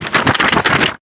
Shake_v1_wav.wav